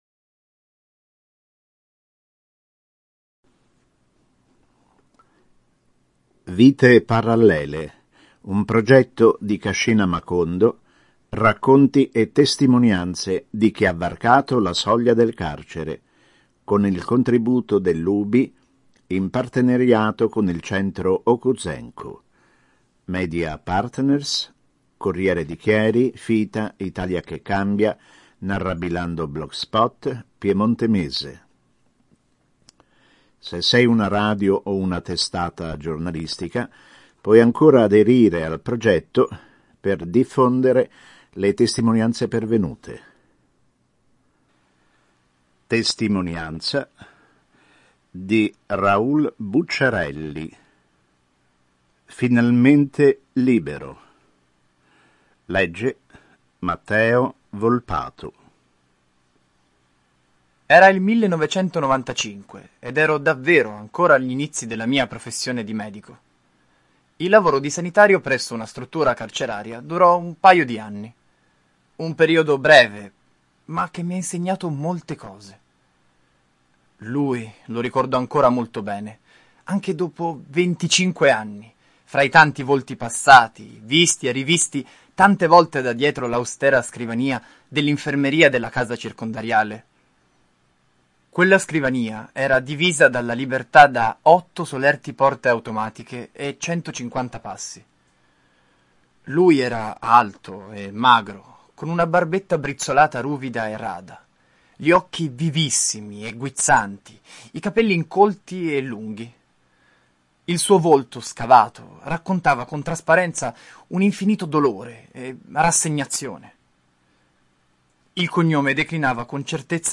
il file audio, con testo registrato, in formato mp3.